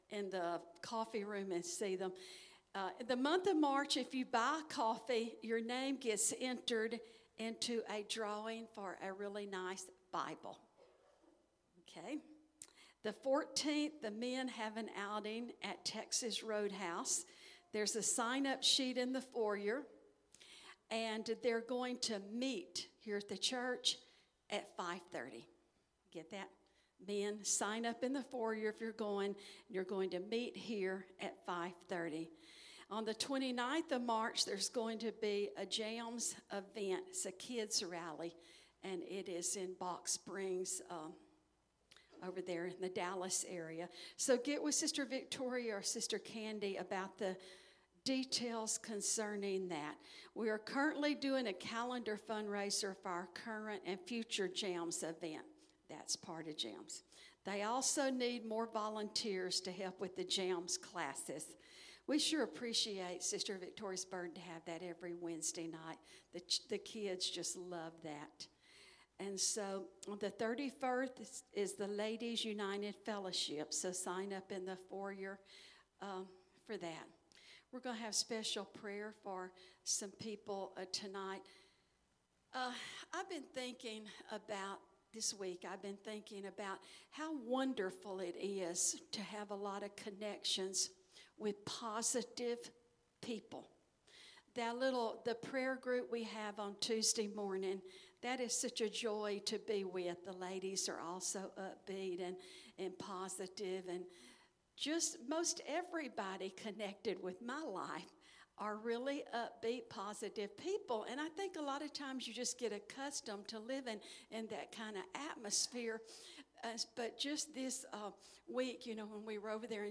Sermons by United Pentecostal Church